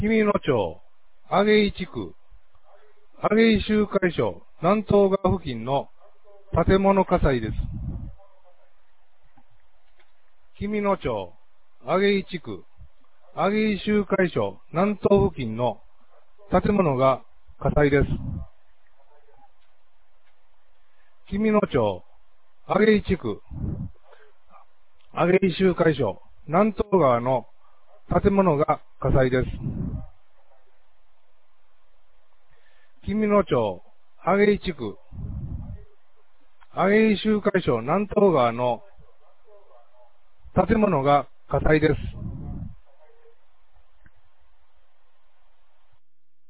2023年06月03日 19時07分に、紀美野町より全地区へ放送がありました。